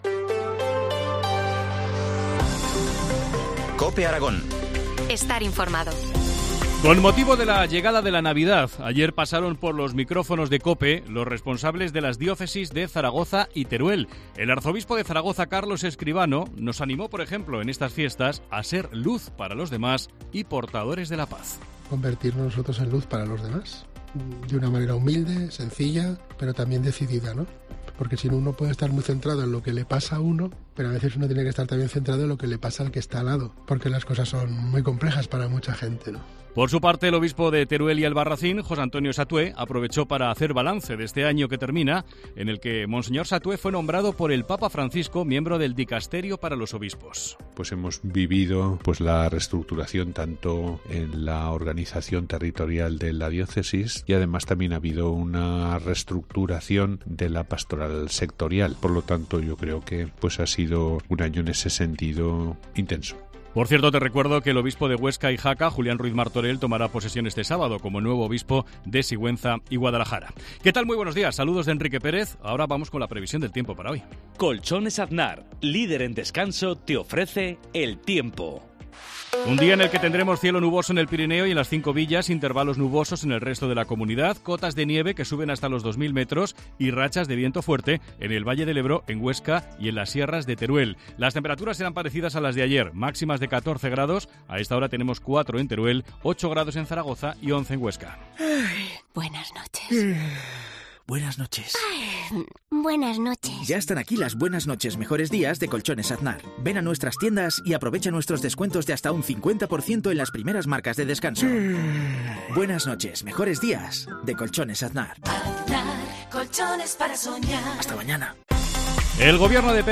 AUDIO: Titulares del día en COPE Aragón